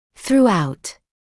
[θruː’aut][сруː’аут]на всем протяжении (времени, явления); повсюду; повсеместно